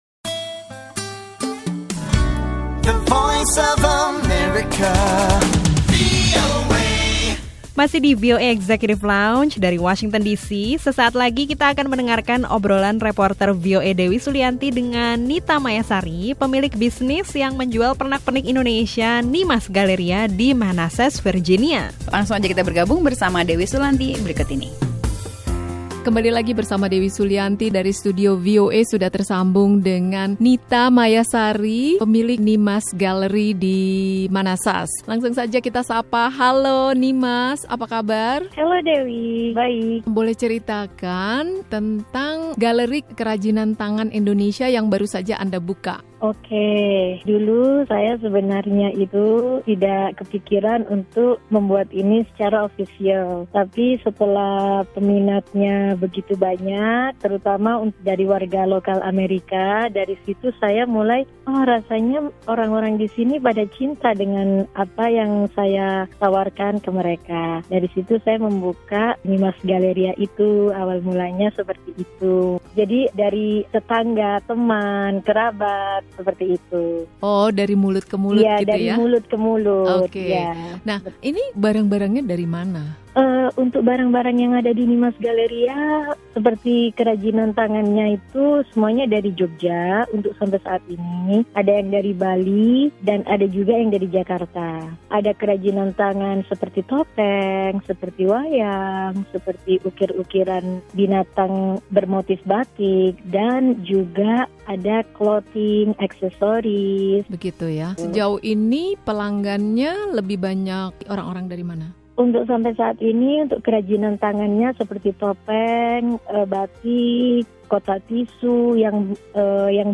Obrolan bersama seorang warga Indonesia yang memiliki galeri yang menjual pernak-pernik dan pakaian dari Indonesia, di negara bagian Virginia.